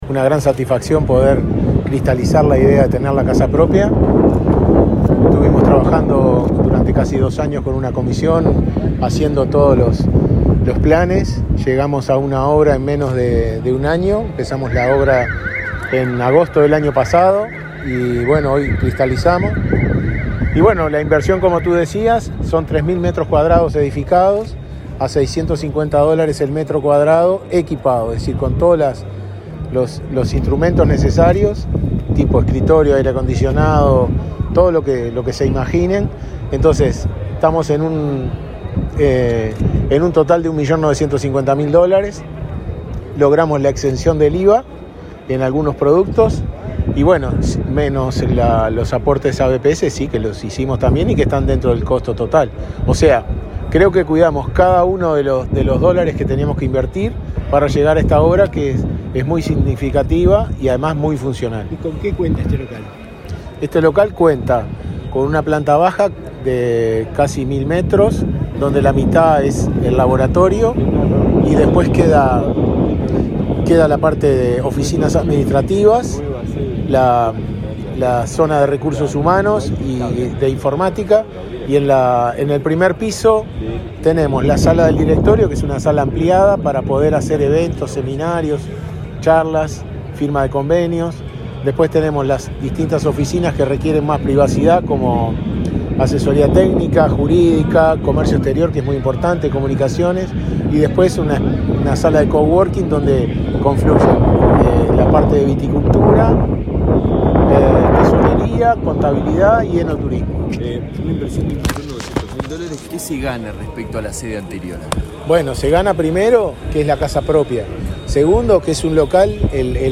Declaraciones a la prensa del presidente de Inavi
Declaraciones a la prensa del presidente de Inavi 21/06/2022 Compartir Facebook X Copiar enlace WhatsApp LinkedIn El presidente Luis Lacalle Pou encabezó el acto de inauguración de la sede del Instituto Nacional de Vitivinicultura (Inavi), ubicada en la localidad de Las Piedras, Canelones. Al finalizar el acto, el presidente del Inavi, Ricardo Cabrera, dio detalles del nuevo edificio.